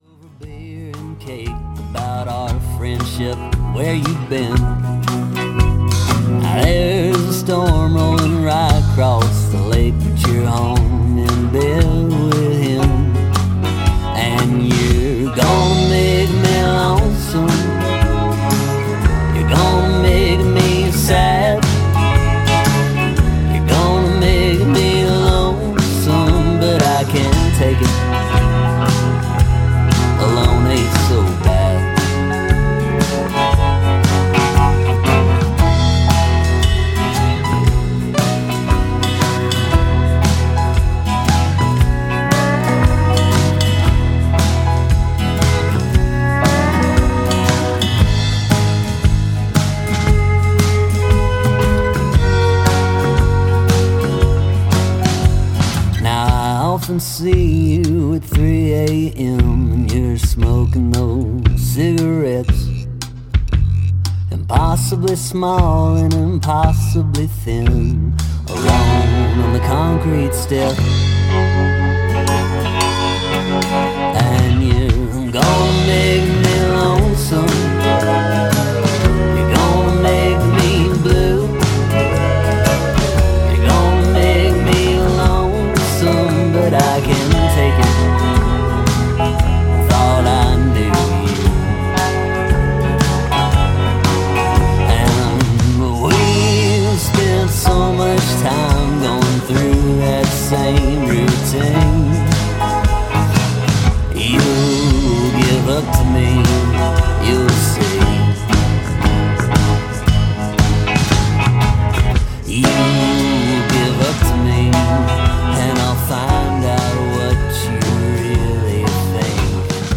Alt-Country, Folk